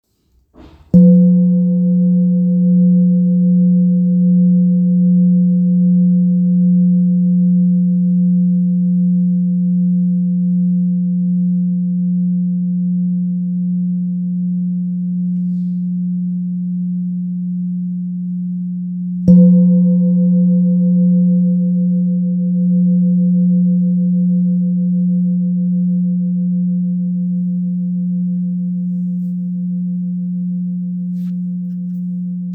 Kopre Singing Bowl, Buddhist Hand Beaten, Antique Finishing
Material Seven Bronze Metal